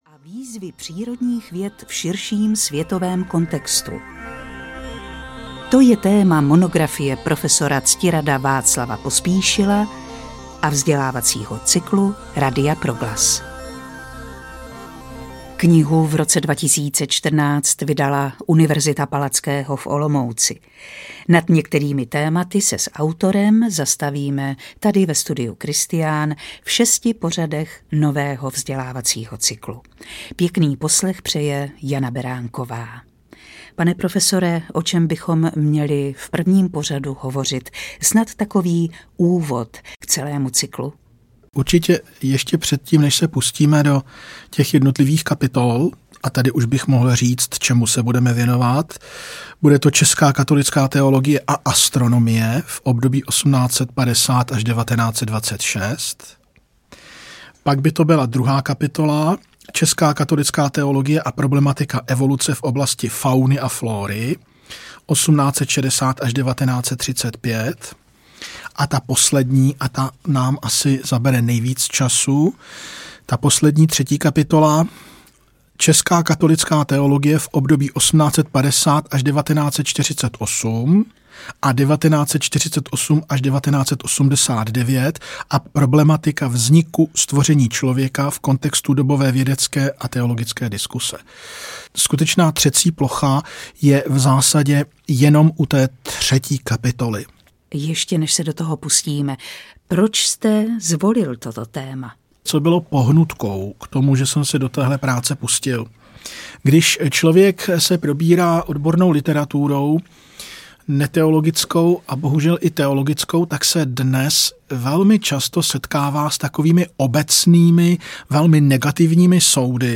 Audio knihaČeská katolická teologie 1850-1950 a přírodní vědy
Ukázka z knihy